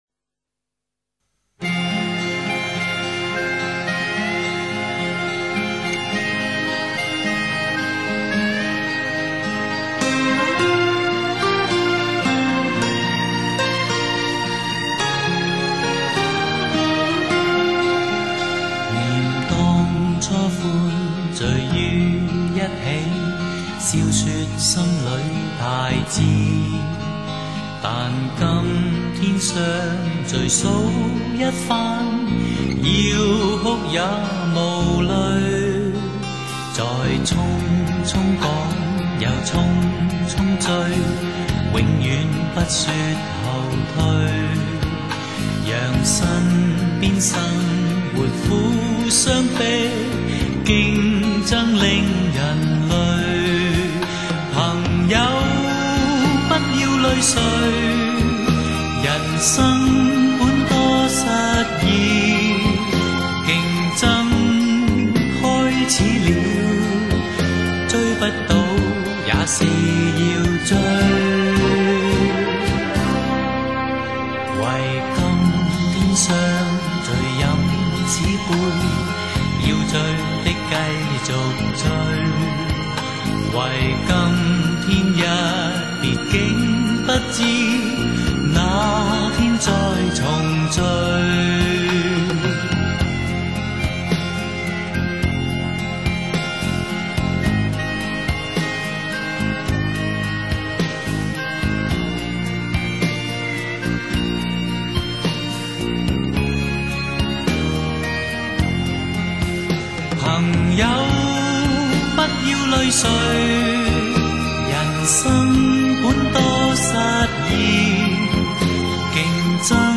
华语怀旧